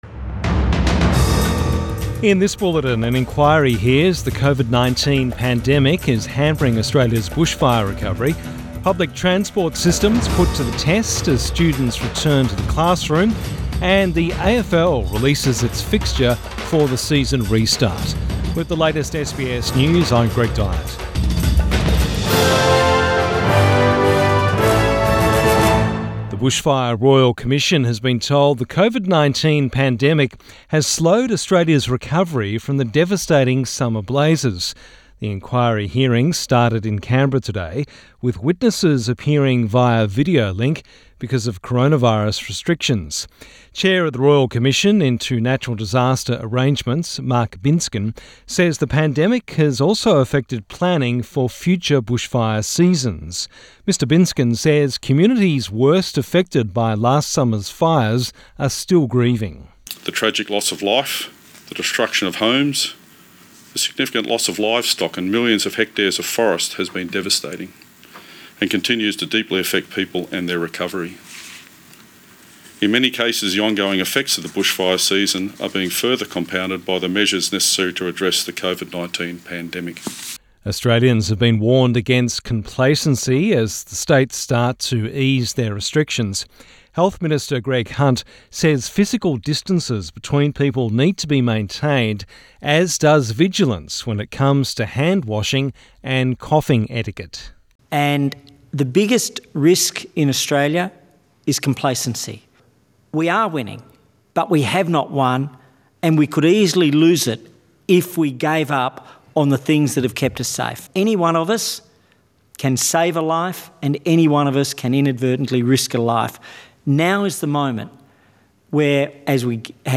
PM bulletin 25 May 2020